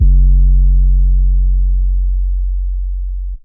808 (Sizzle).wav